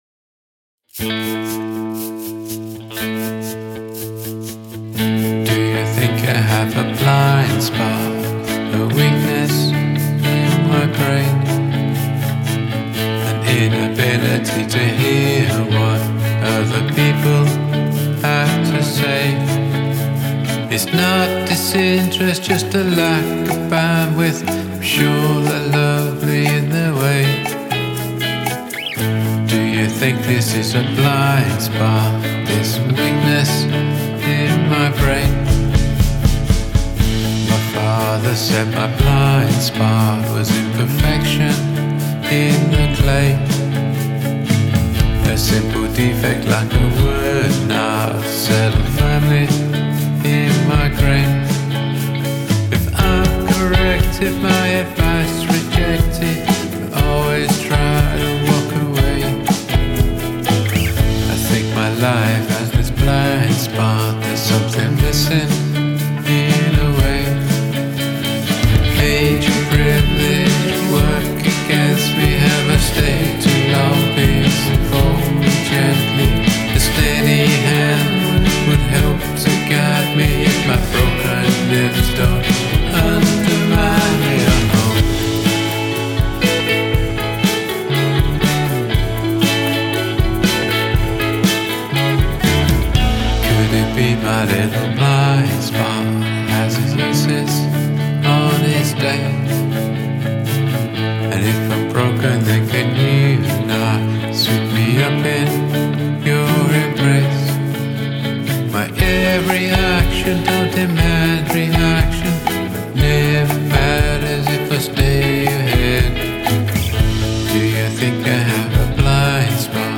Hand percussion
I like the stereo guitars.
Nice guitar solo.